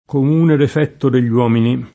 difetto
difetto [ dif $ tto ] s. m.